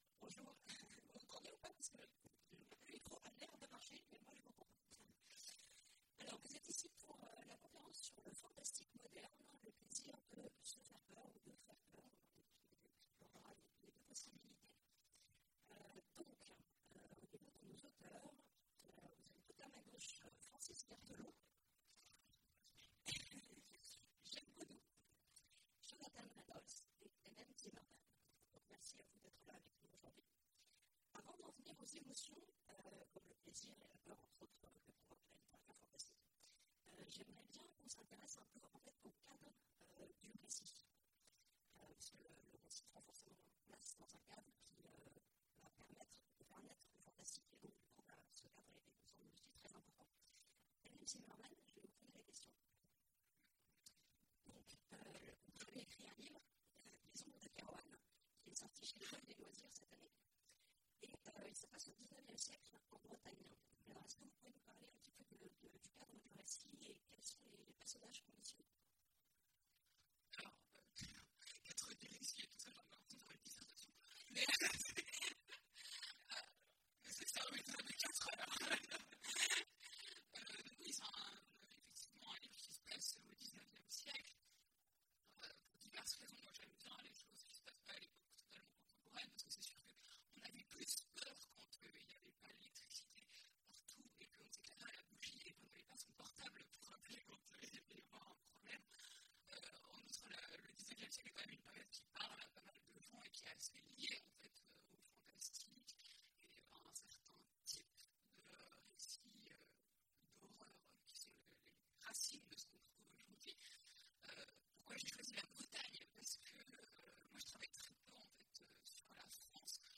Imaginales 2016 : Conférence Le fantastique moderne…